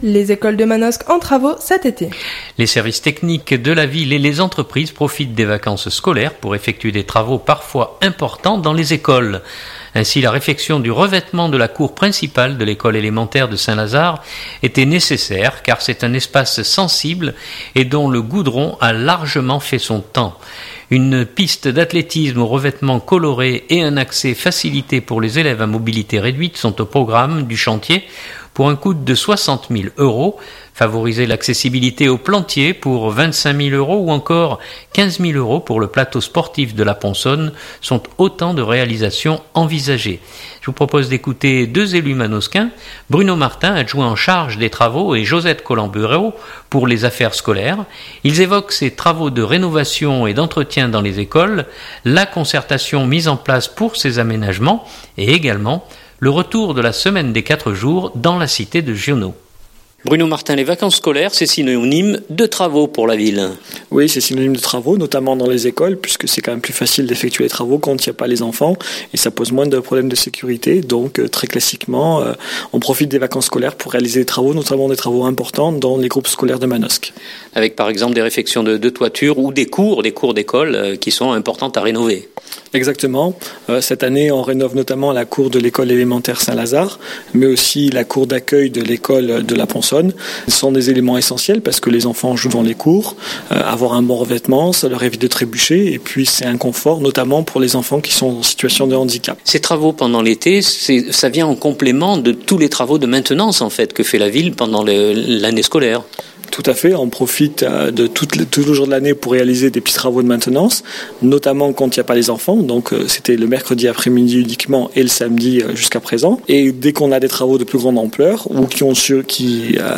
Je vous propose d’écouter deux élus manosquins : Bruno Martin adjoint en charge des travaux et Josette Colombéro pour les affaires scolaires. Ils évoquent ces travaux de rénovation et d’entretien dans les écoles, la concertation mise en place pour ces aménagements, et également le retour de la semaine des quatre jours dans la cité de Giono. écouter : 2017-07-21- Manosque - écoles travaux.mp3 (5.35 Mo)